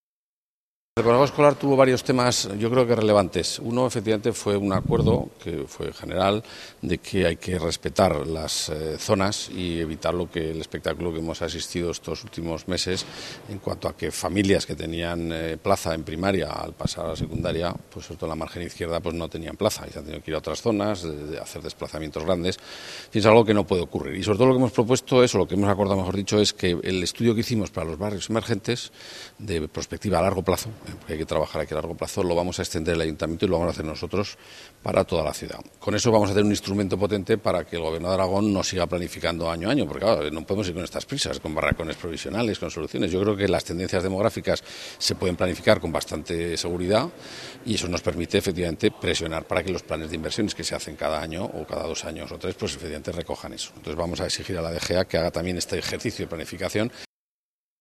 El Consejo Escolar de Zaragoza ha exigido al Gobierno de Aragón que realice una planificación a más largo plazo que la que ahora establece, con el objetivo de adecuarse mejor a las necesidades educativas reales de Zaragoza. El Consejero de Cultura, Jerónimo Blasco, detalla esta decisión: